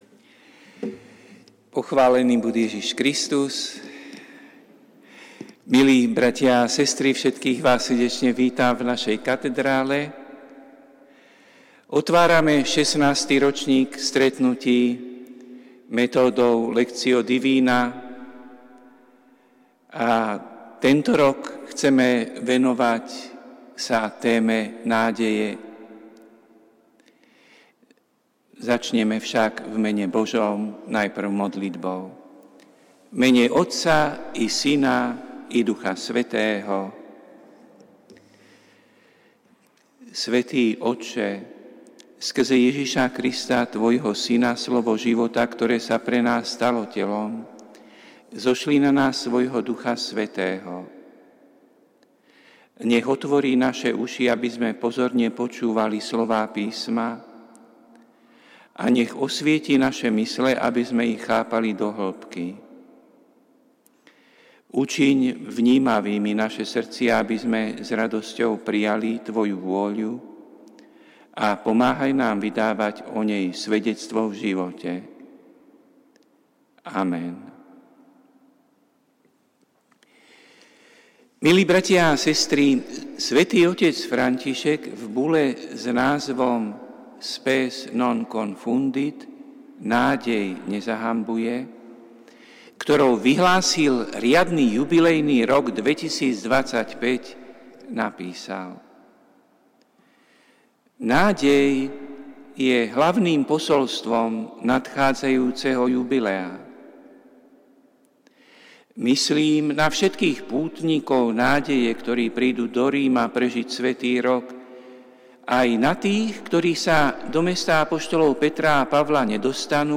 Prinášame plný text a audio záznam z Lectio divina, ktoré odznelo v Katedrále sv. Martina 4. septembra 2024.